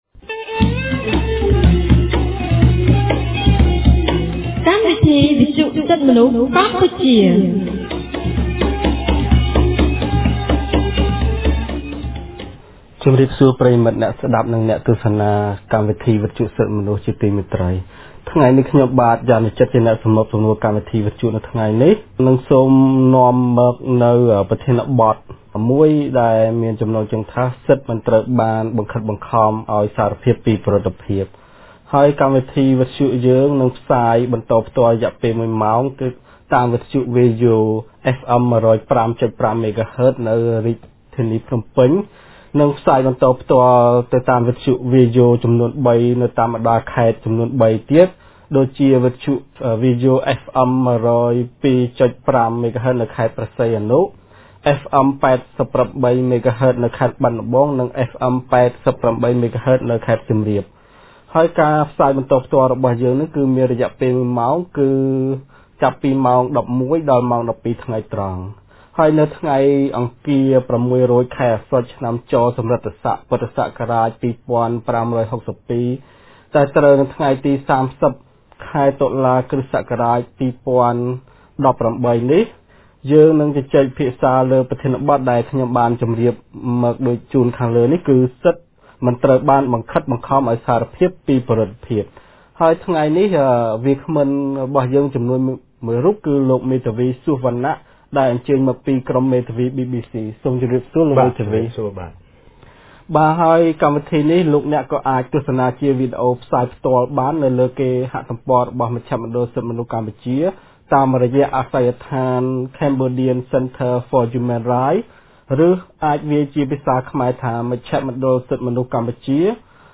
កាលពីថ្ងៃទី៣០ ខែតុលា ឆ្នាំ២០១៨ គម្រាងសិទ្ធិទទួលបានការជំនុំជម្រះដោយយុត្តិធម៌នៃមជ្ឈមណ្ឌលសិទ្ធិមនុស្សកម្ពុជា បានរៀបចំកម្មវិធីវិទ្យុក្រោមប្រធានបទស្តីពី សិទ្ធិមិនត្រូវបានបង្ខិតបង្ខំឲ្យសារភាពពីពិរុទ្ធភាព។